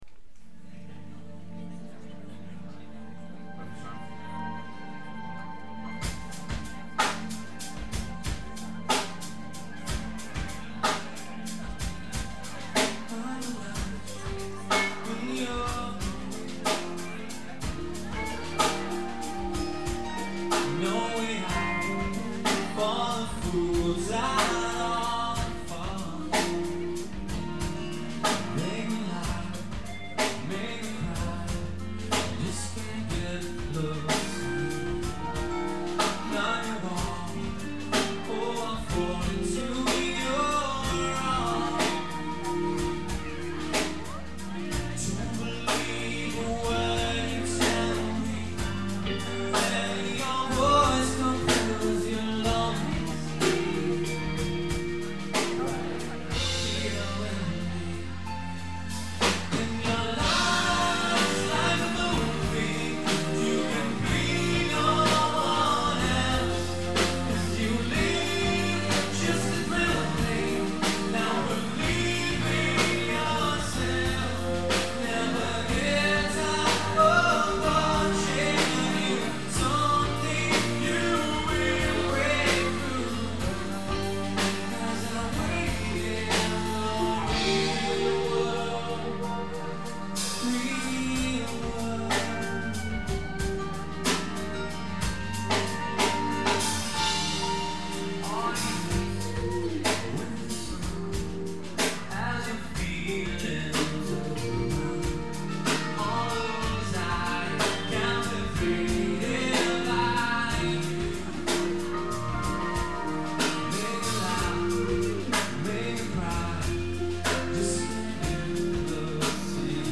Band Set